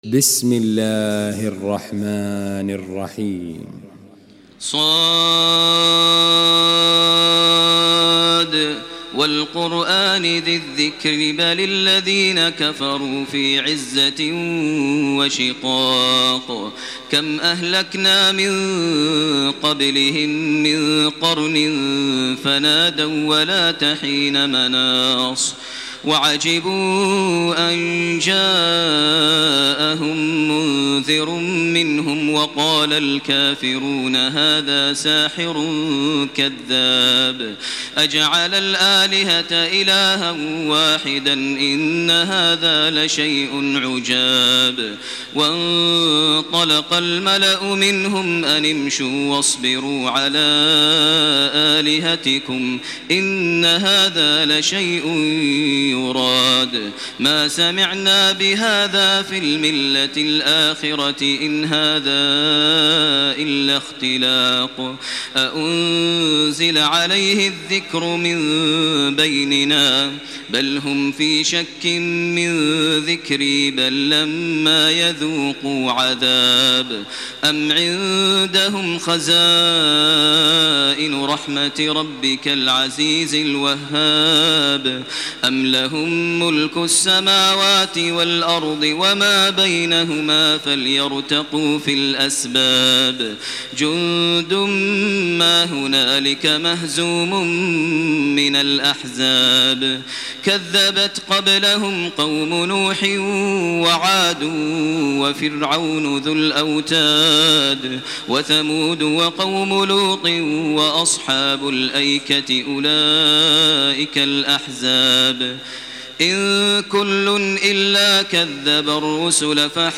تراويح ليلة 23 رمضان 1428هـ سورتي ص و الزمر Taraweeh 23 st night Ramadan 1428H from Surah Saad and Az-Zumar > تراويح الحرم المكي عام 1428 🕋 > التراويح - تلاوات الحرمين